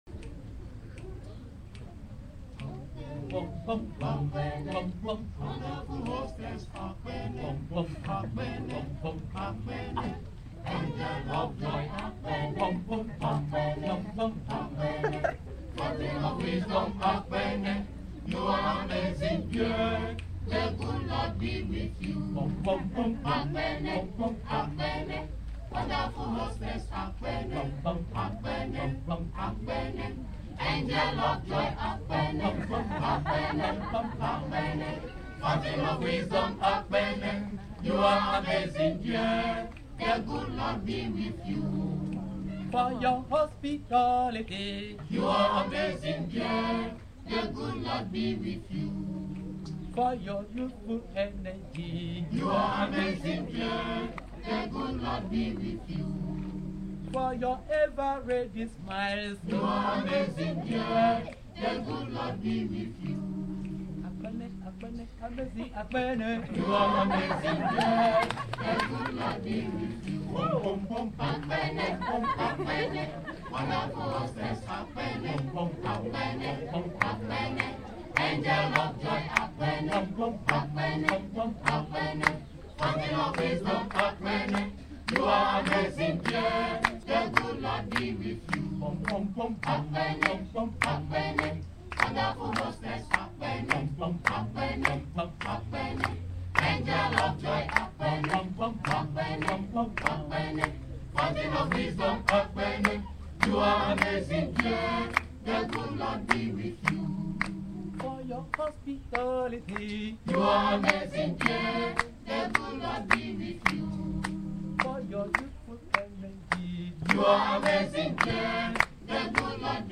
Departure ceremony:
7 Akpene choir
African Barbershop - The Akpene Choir
Here is the song they were singing.